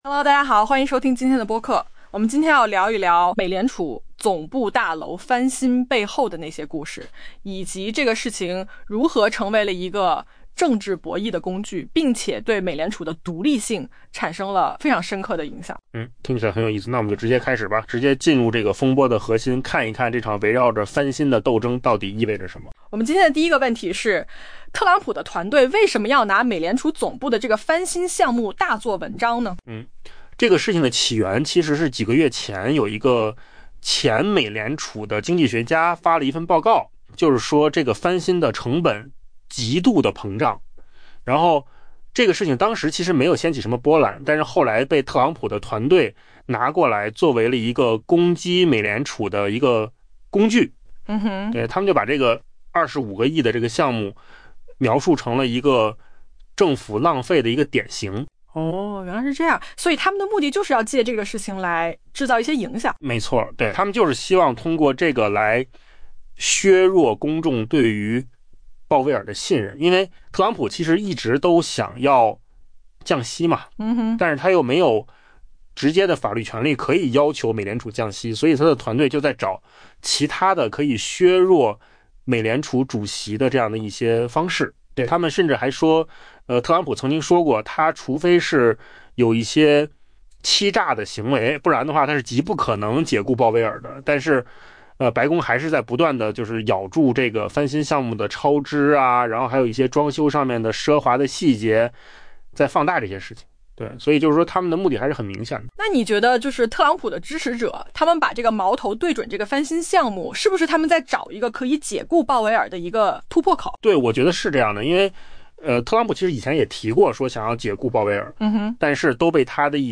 AI播客：换个方式听新闻 下载mp3 音频由扣子空间生成 今年早些时候，一位前美联储经济学家发布了一份关于该央行总部翻新成本飙升的报告，当时鲜有人关注。